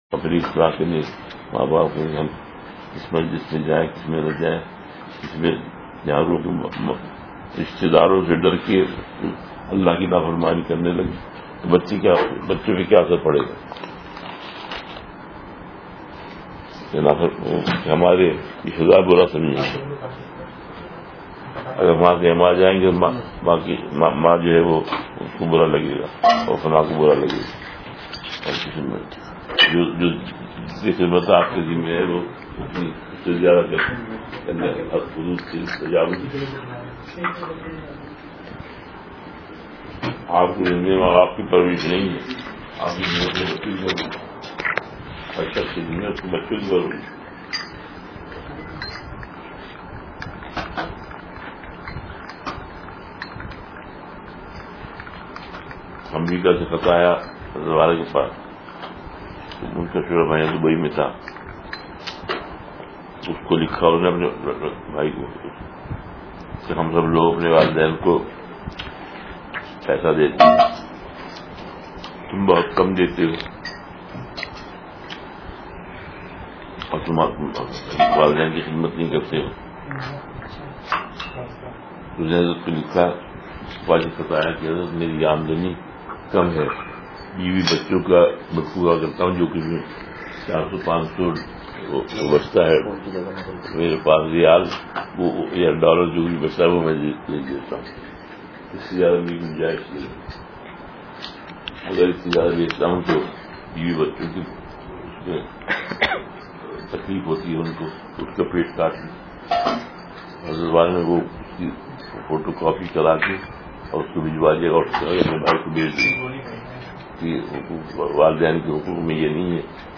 (    نصیحت ۔۲۹ اپریل ۲۰۱۴ء )